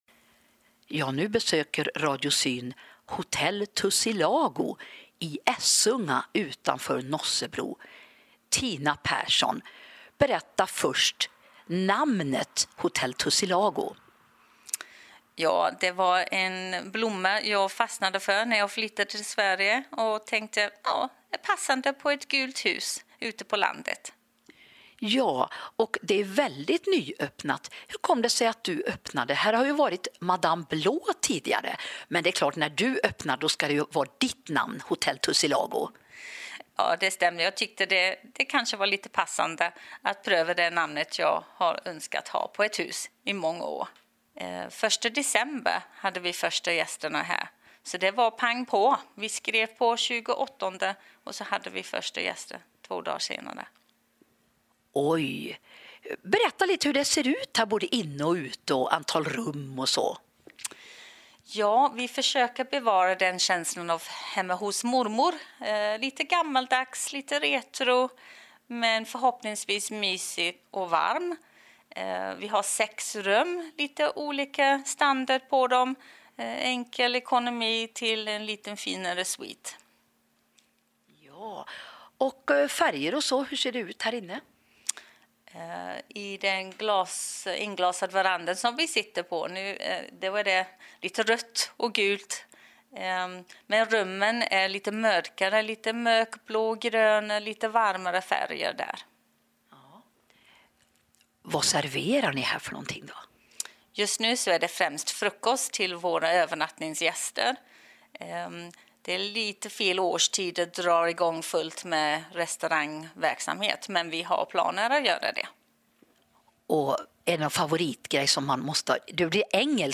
En trevlig intervju.